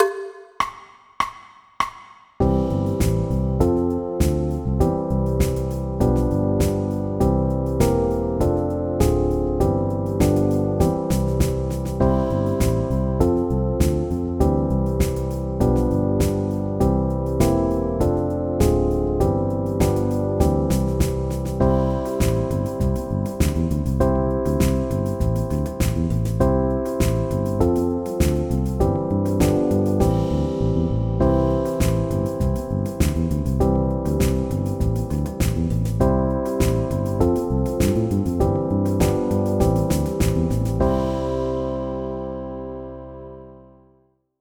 Accompaniment Music Files
Amys-In-Antartica-Accomp.wav